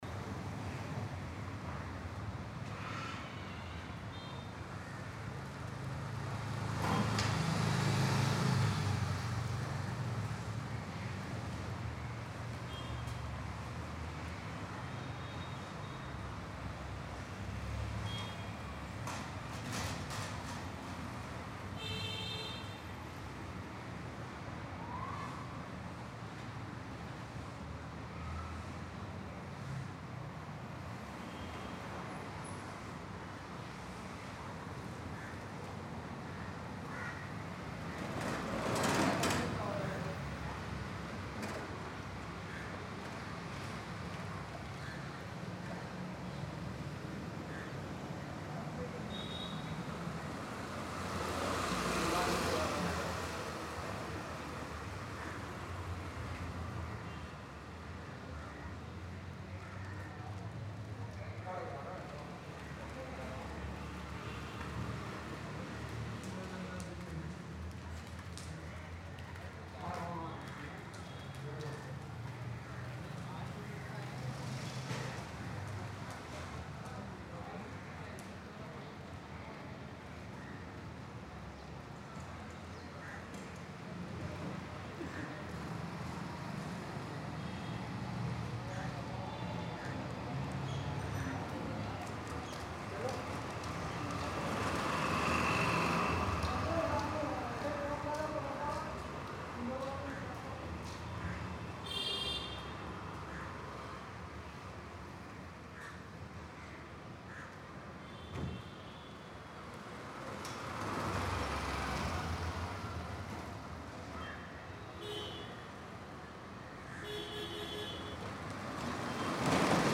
Sunday Morning Vibes captures the calm, gentle atmosphere of an early weekend morning. Soft environmental sounds blend naturally—distant birds, light breeze, subtle neighbourhood activity, and relaxed human presence. This ambience creates a peaceful mood that feels warm, refreshing, and unhurried.
Morning Atmosphere
Calm & Refreshing
Soft Outdoor Stere
Nature / Ambient Life